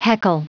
Prononciation du mot heckle en anglais (fichier audio)
heckle.wav